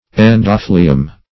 endophloeum - definition of endophloeum - synonyms, pronunciation, spelling from Free Dictionary
endophloeum.mp3